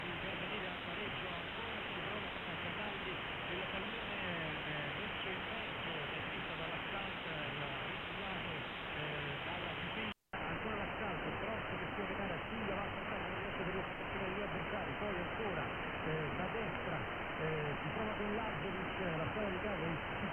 Both devices were operated with the same settings and were supplied with the antenna signal via the antenna distributor of Elad ASA-62.
Second 10 - 20> Winradio G33DDC Excalibur Pro
AMS-8KHz
The audio comparisons of long, medium and shortwave were made with the Kreuzloop RLA4E / 2.